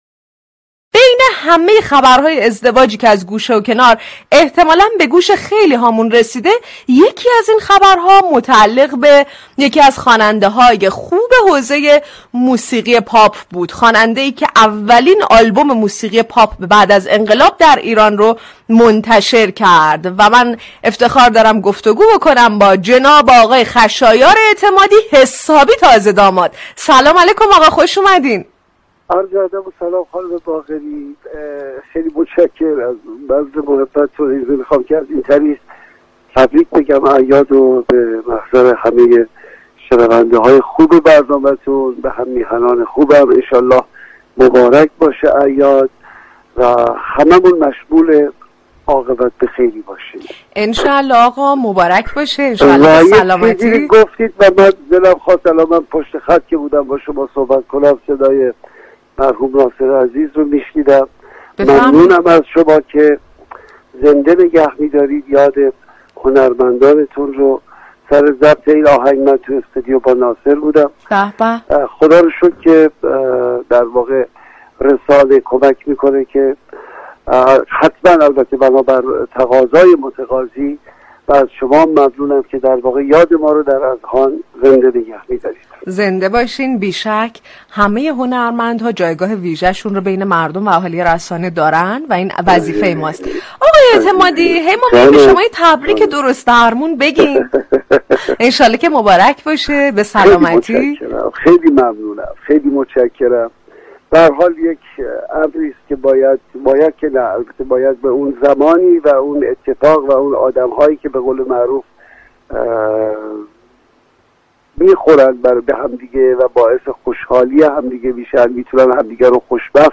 خشایار اعتمادی در گفتگو با رادیو صبا عنوان كرد، از مخاطبان گلایه مندم و با عرصه حرفه ای موسیقی خداحافظی می كنم
خشایار اعتمادی در گفتگو با «صباهنگ» رادیو صبا درباره خداحافظی اش از دنیای موسیقی توضیحاتی ارائه كرد.